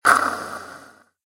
wallSlide.ogg